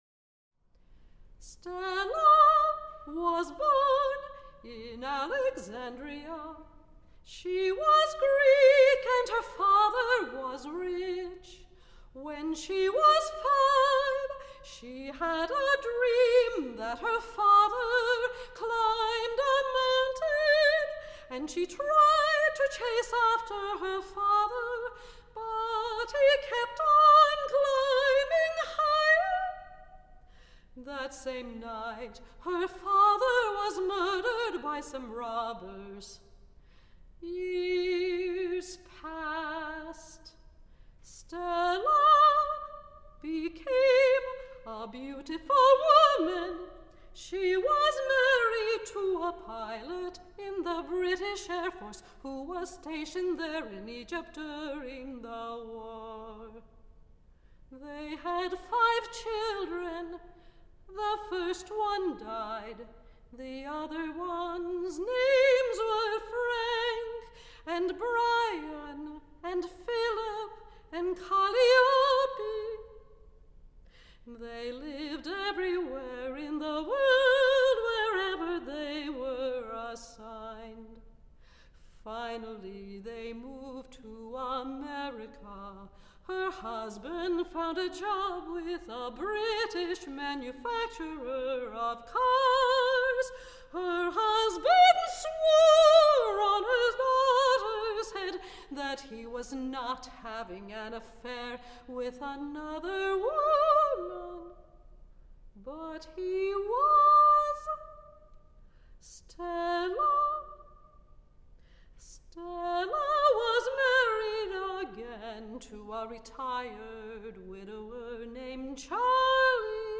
vocal music
soprano